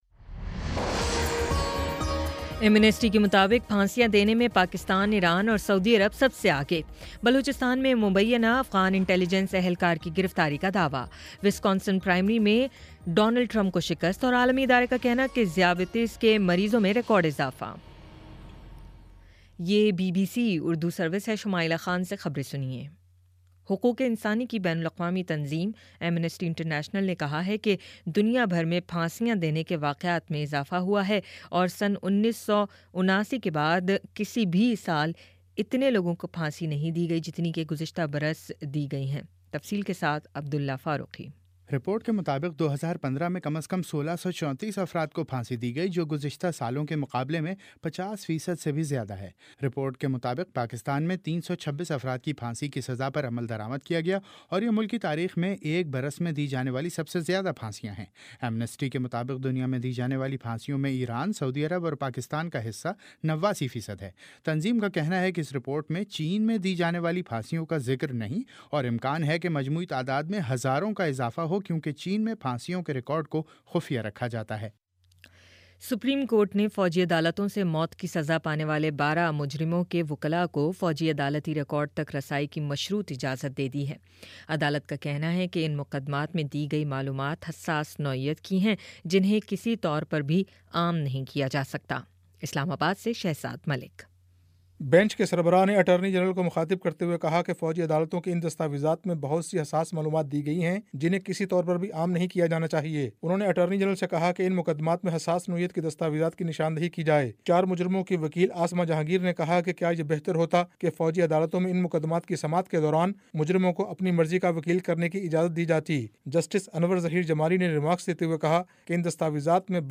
اپریل 06 : شام پانچ بجے کا نیوز بُلیٹن